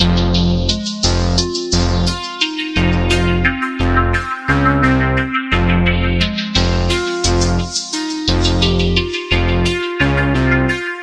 Tag: 87 bpm Hip Hop Loops Synth Loops 950.47 KB wav Key : F